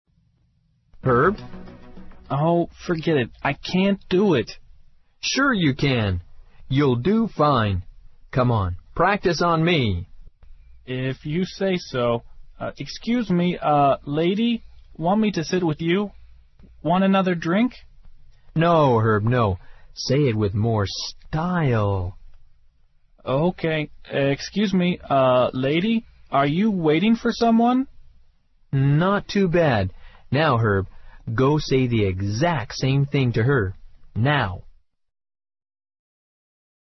《网络社交口语对话》收集了众多关于网络社交的口语对话，对提高你的口语大有用处，值得你收藏。